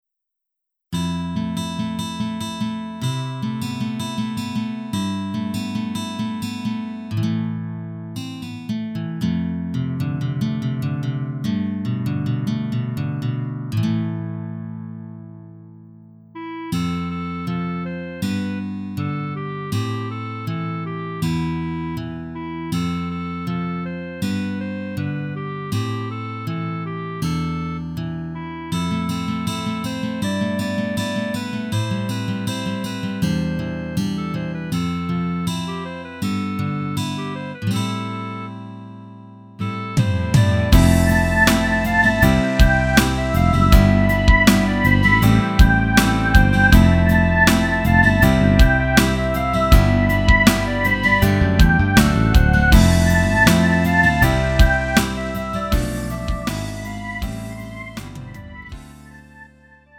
음정 원키 3:39
장르 구분 Lite MR